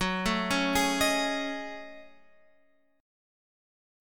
F# Minor 6th Add 9th